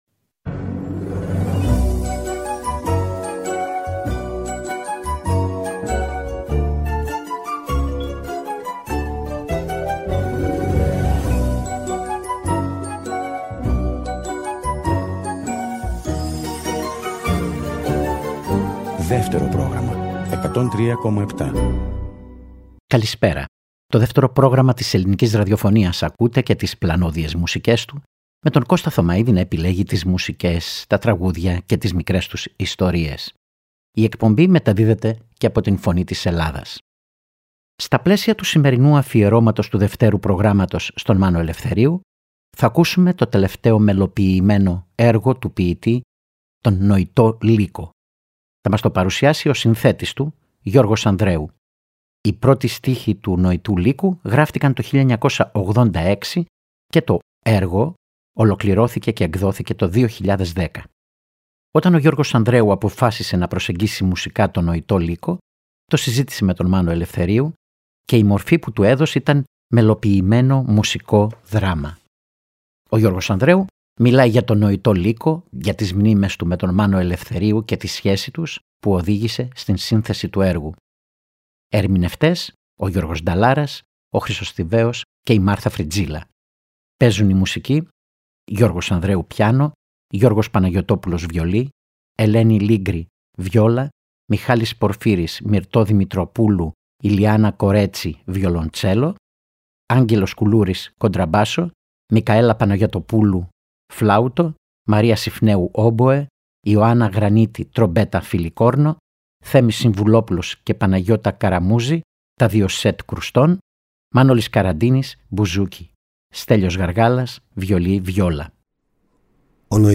φιλοξένησε τον συνθέτη Γιώργο Ανδρέου